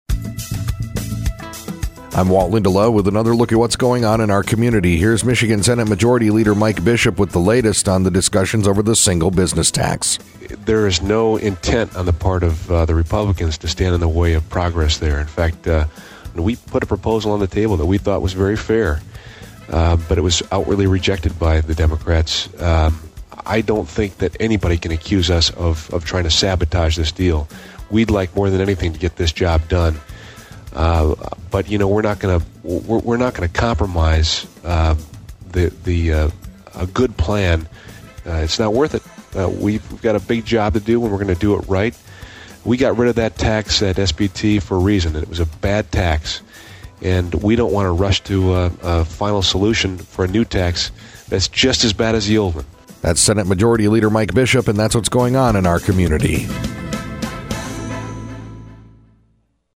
INTERVIEW: Michigan Senate Majority Leader Mike Bishop